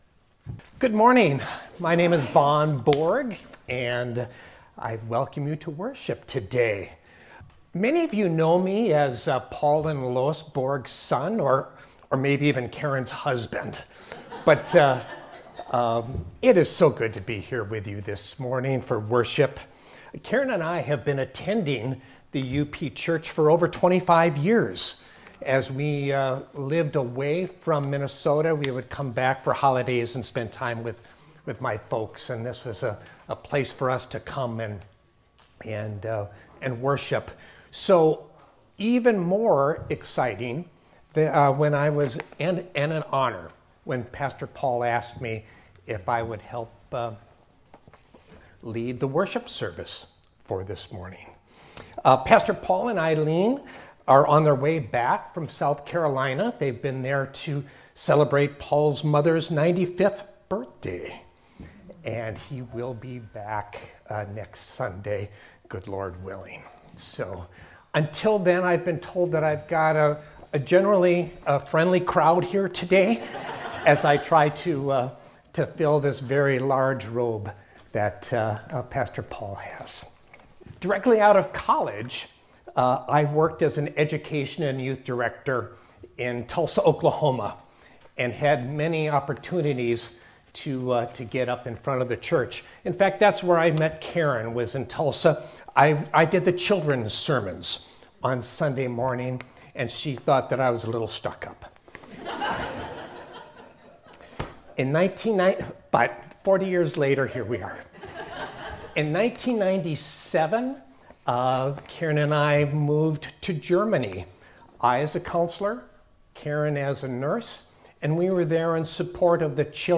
sermon-1.mp3